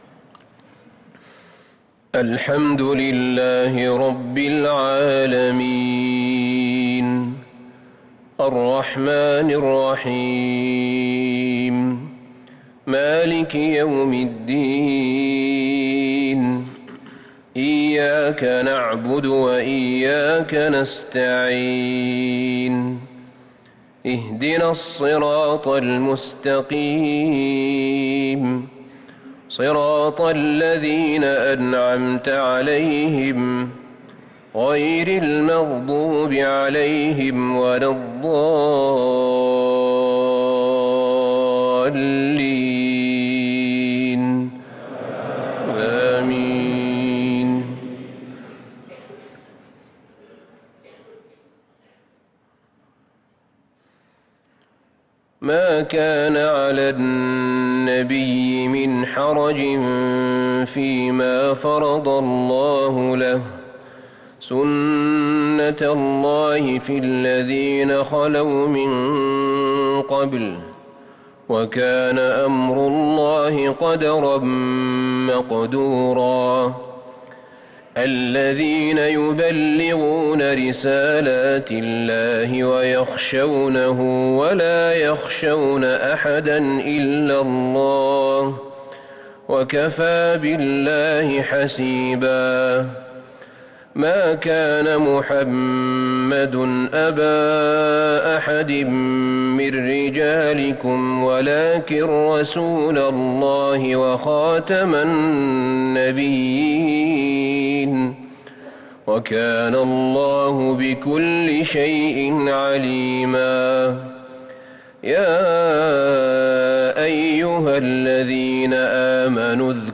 صلاة العشاء للقارئ أحمد بن طالب حميد 2 ربيع الآخر 1444 هـ